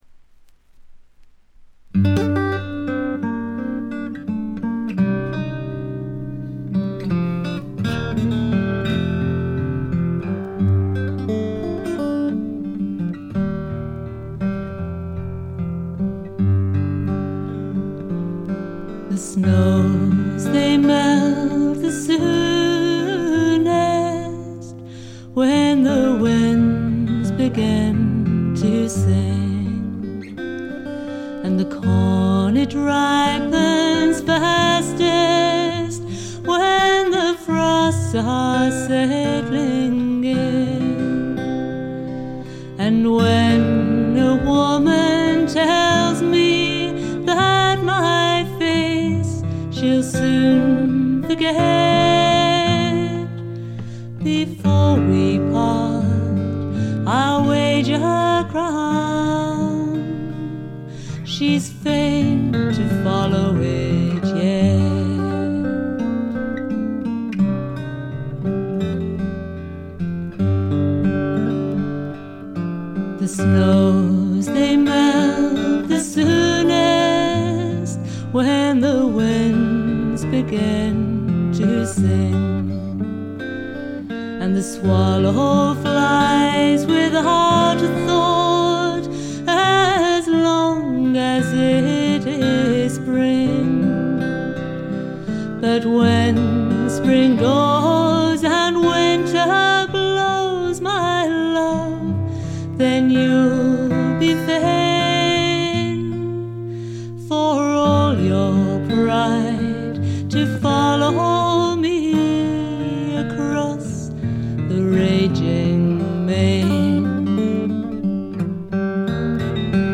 試聴曲は現品からの取り込み音源です。
Vocals
Guitar, Flute